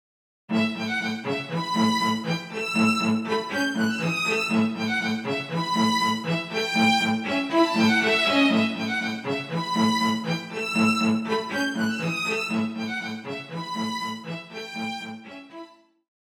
Ein kleines Experiment mit Sequenzen aus der PATTERN Auswahl. Im Audiobeispiel spielt ein Violin- und ein Cello-Pattern gleichzeitig die entsprechenden Parts.